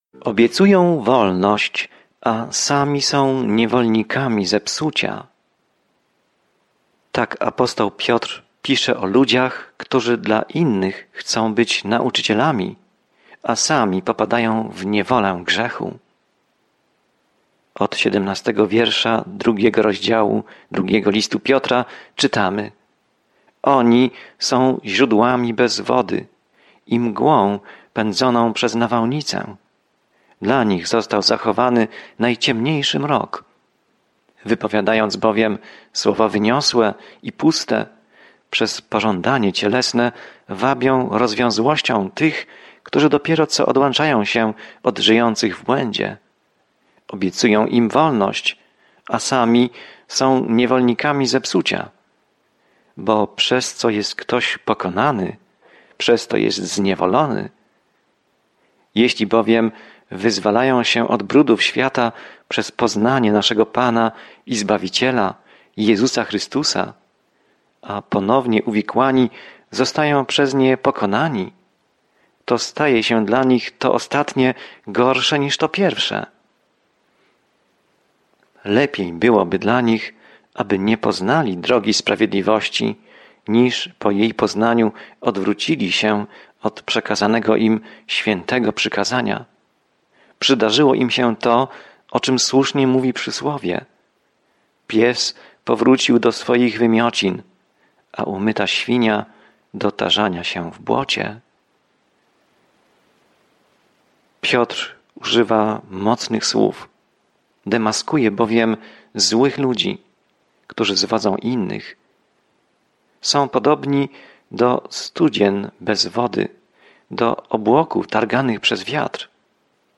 Pismo Święte 2 Piotra 2:21-22 Dzień 7 Rozpocznij ten plan Dzień 9 O tym planie Drugi list Piotra jest w całości poświęcony łasce Bożej – jak nas zbawiła, jak nas utrzymuje i jak możemy w niej żyć – pomimo tego, co mówią fałszywi nauczyciele. Codzienna podróż przez 2 List Piotra, słuchanie studium audio i czytanie wybranych wersetów słowa Bożego.